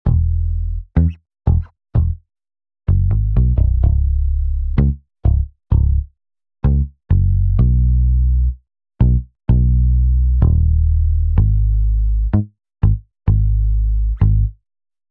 14 bass A1.wav